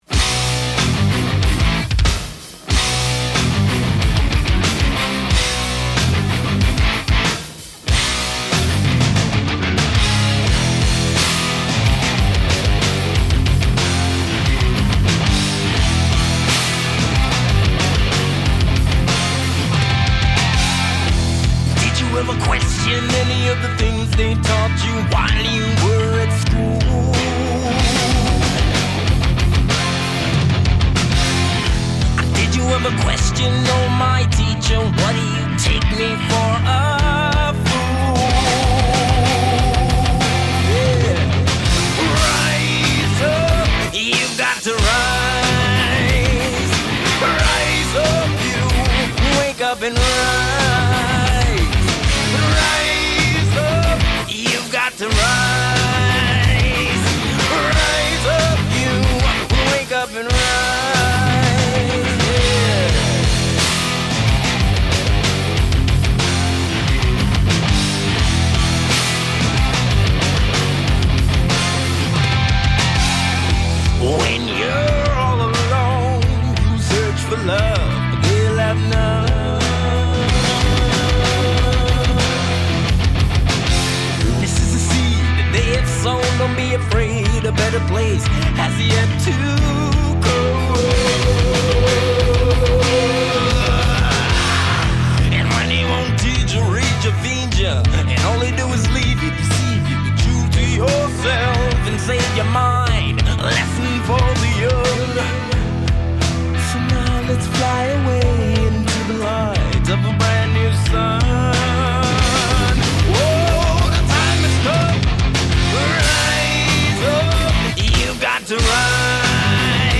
آلبوم پانک/هاردکور
Punk, Hardcore, Reggae